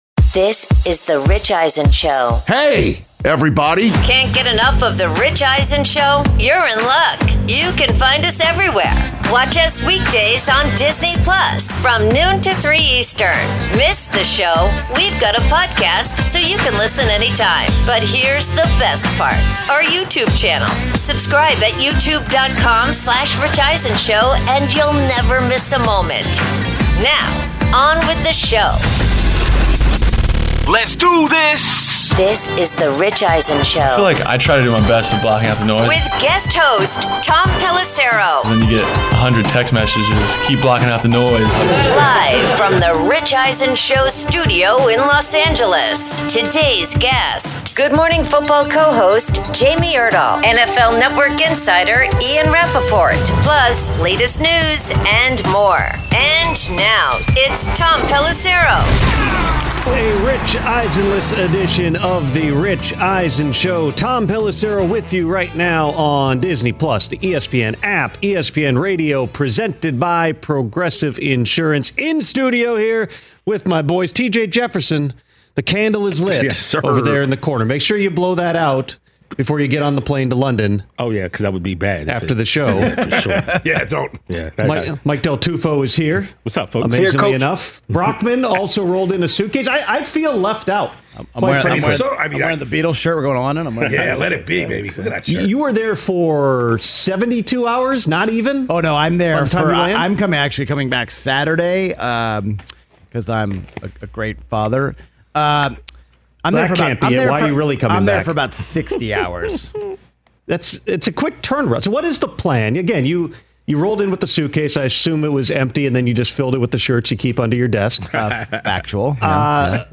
Hour 1: Guest Host Tom Pelissero, Good Morning Football’s Jamie Erdahl
Guest host Tom Pelissero previews Steelers vs Bengals in Week 7 and explains why there will be some extra spice added to their AFC North rivalry after Pittsburgh head coach Mike Tomlin bashed the Browns for trading Joe Flacco in-division to Cincinnati.